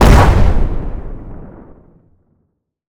explosion_large_10.wav